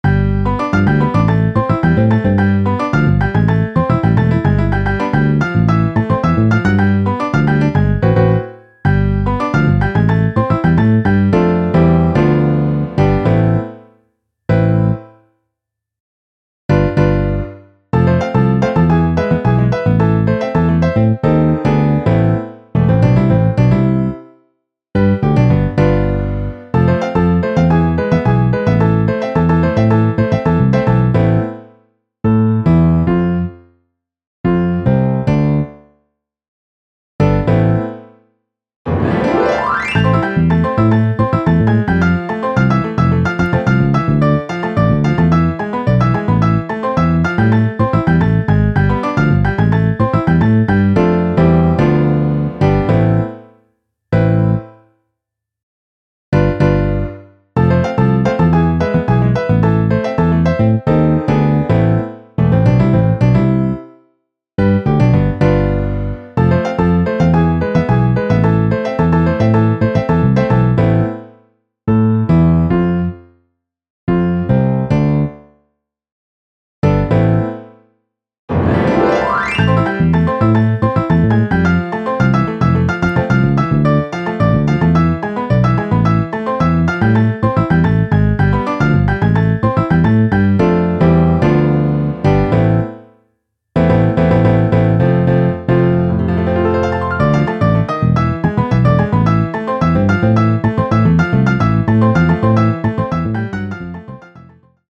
Saxo Alto, 2X Trompetas, 2X Trombones, Piano, Bajo